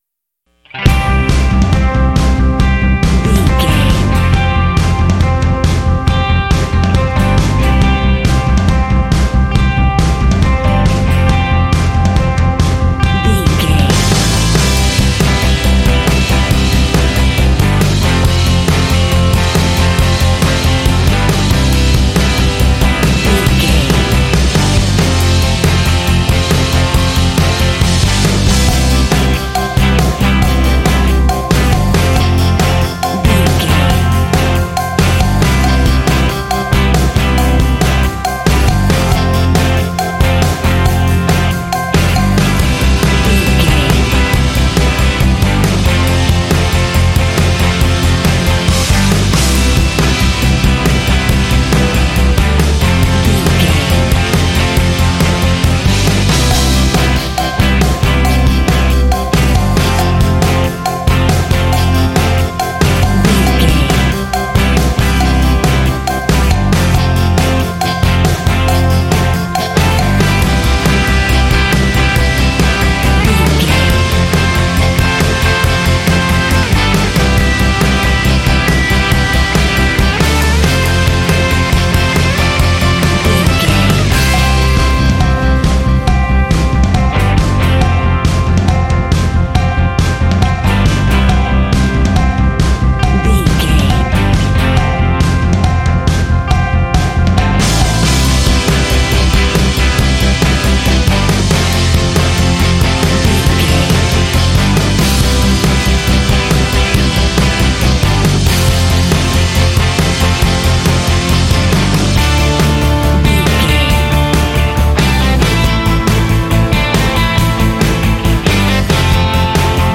Ionian/Major
energetic
uplifting
drums
electric guitar
bass guitar